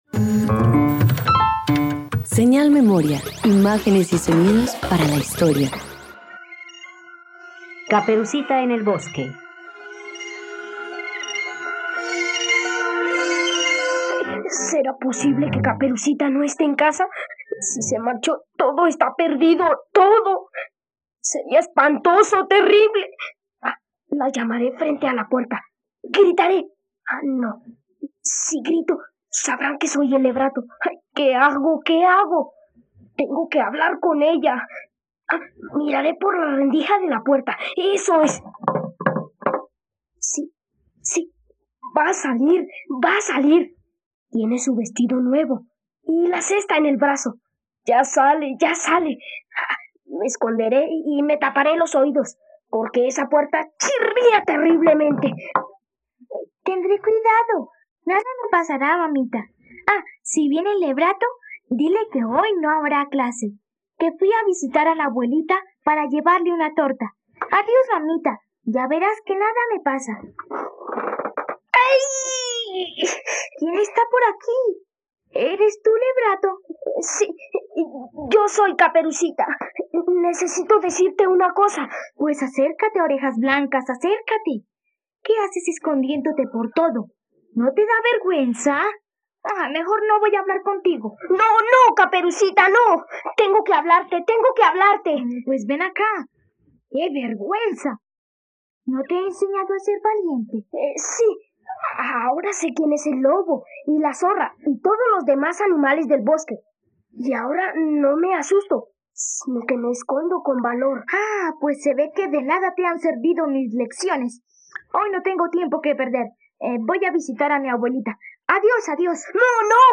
Caperucita en el bosque - Radioteatro dominical | RTVCPlay
radioteatro